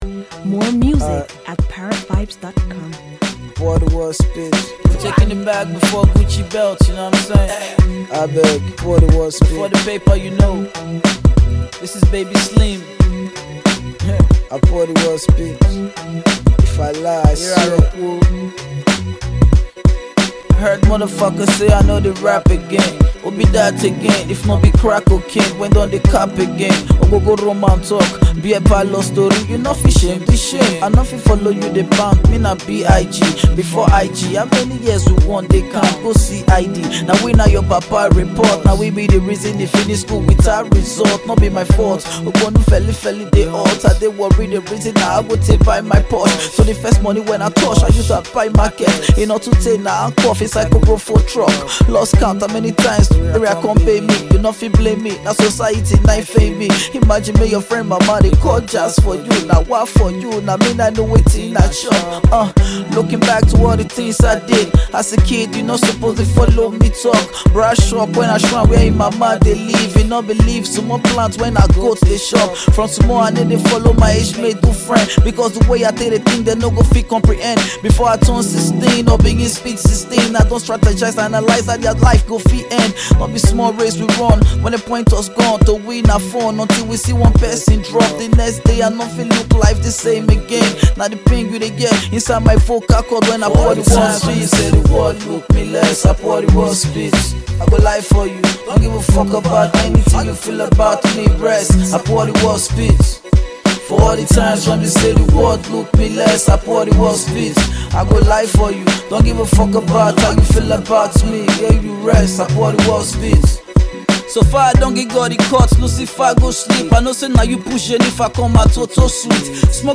Versatile Nigerian rap genius and performer